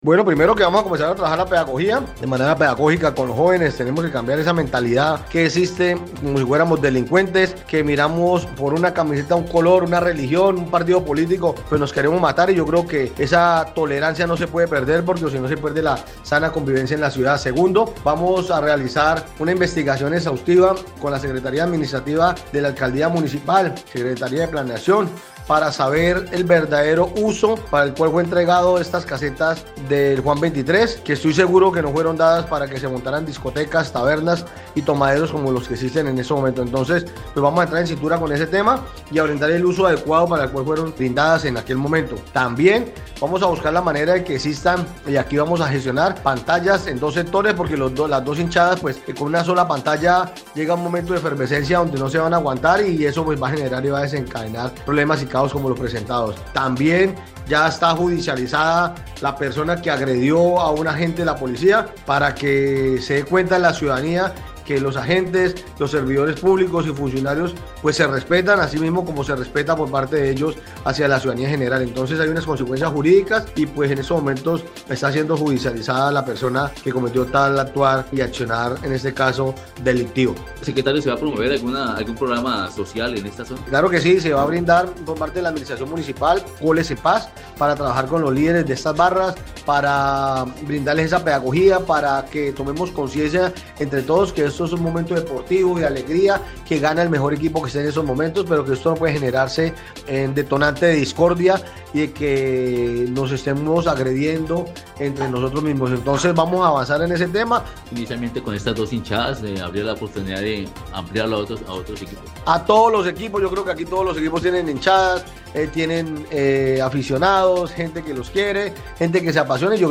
Así lo dio a conocer el secretario de gobierno municipal, Carlos Mora Trujillo, quien dijo, además, que, la decisión se adoptó en medio del más reciente Comité Civil de Convivencia, donde también se aperturo una investigación para determinar cuál es el uso real del suelo donde funcionan las denominadas ´casetas del Juan´.